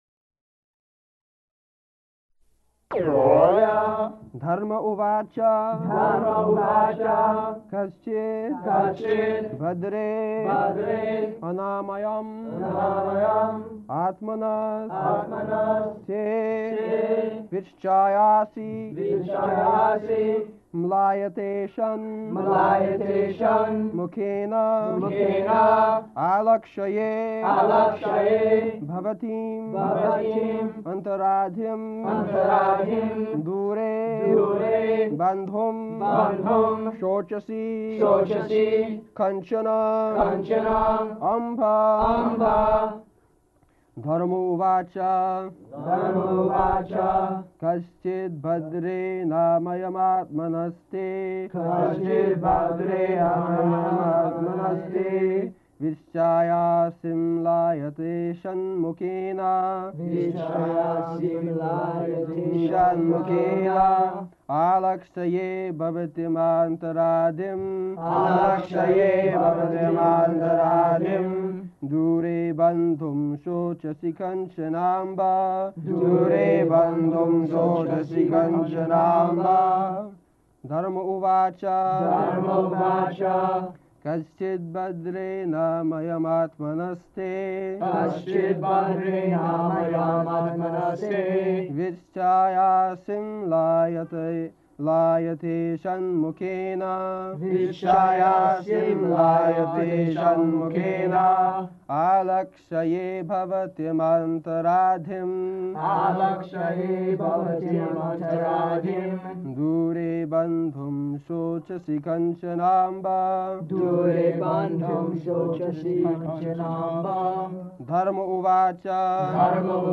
Location: Honolulu
[leads chanting of verse] [devotees repeat]